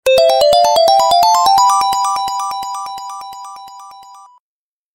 Video Game Sound Effect
Notification Sound